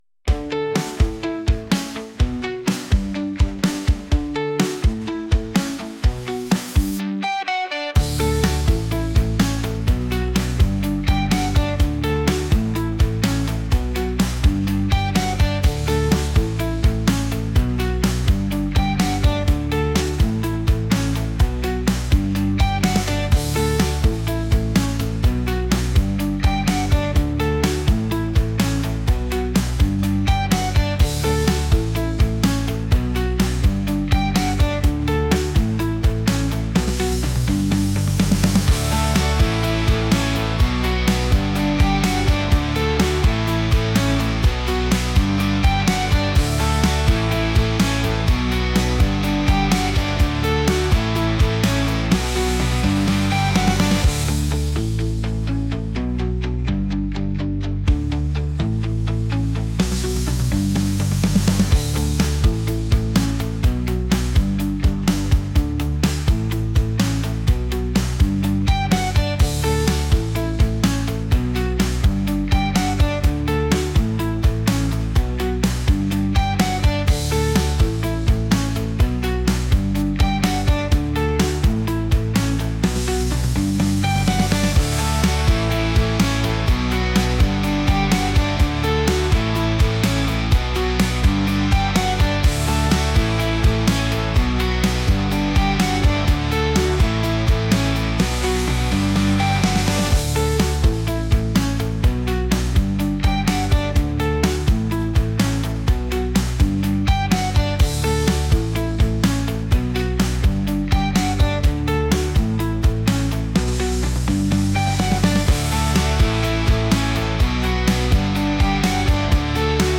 catchy | upbeat | pop